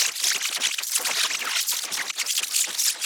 FAST_FORWARD_LOOP_02.wav